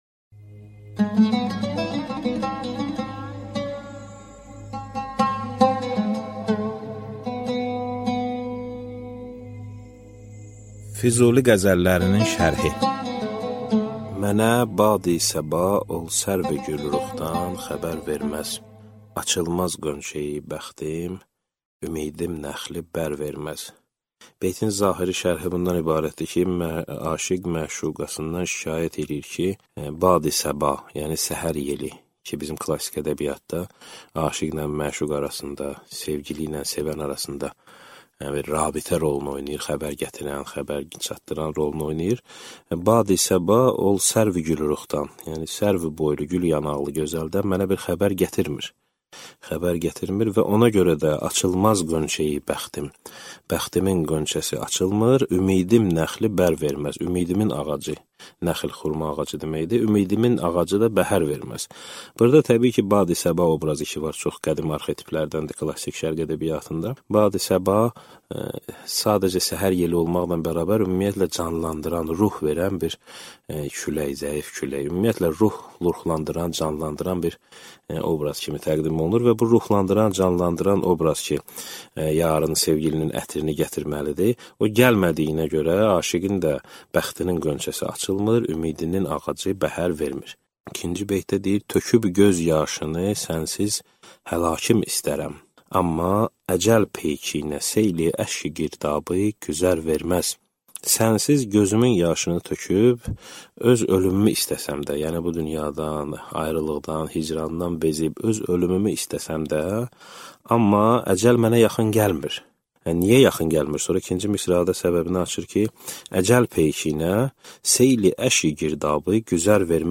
Аудиокнига Məhəmməd Füzulinin qəzəllərinin şərhi | Библиотека аудиокниг